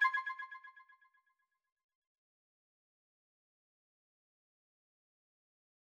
back_style_4_echo_004.wav